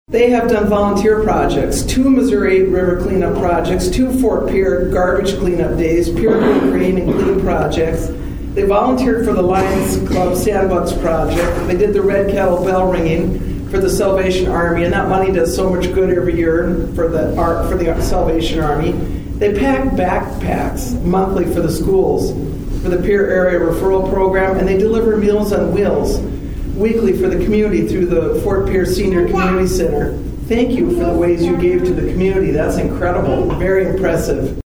South Dakota Supreme Court Justice Janine Kern speaks at the Sixth Circuit Drug and DUI Treatment Court Program Graduation in Pierre Nov. 12, 2025.